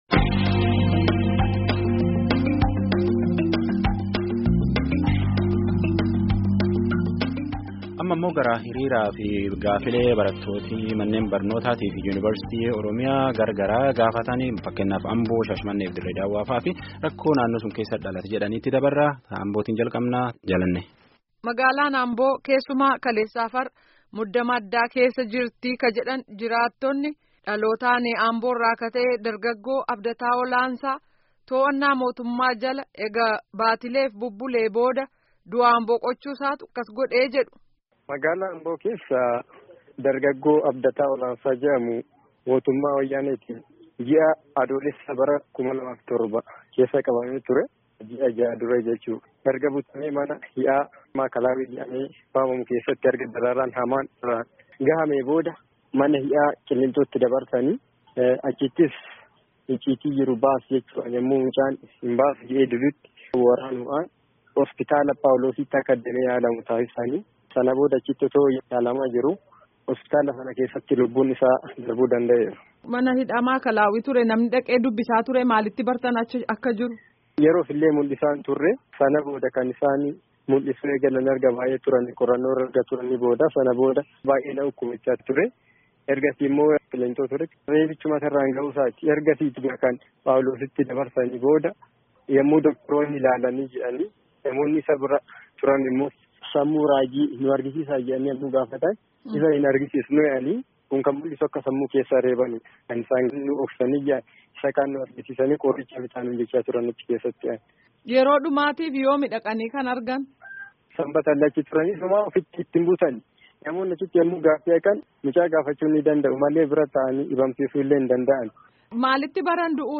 Gabaasa Guutuu